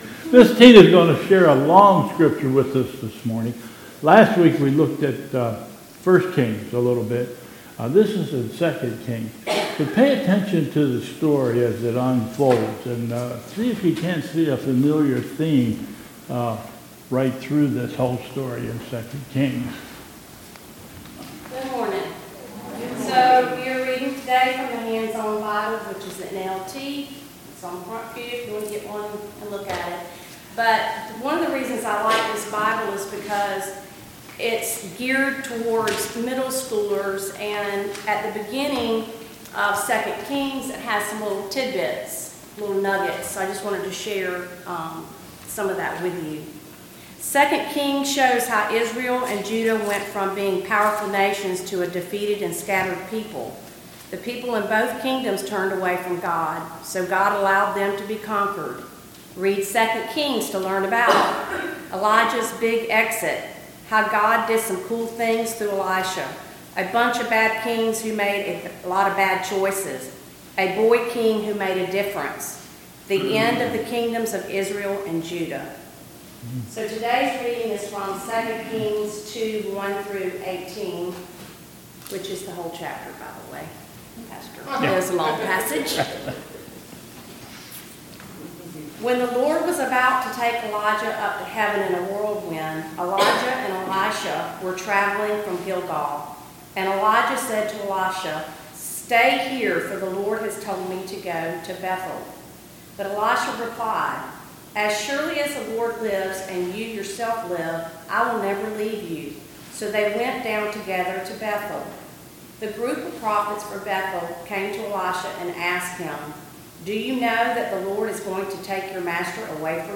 2022 Bethel Covid Time Service
Reading from 2 Kings 2:1-18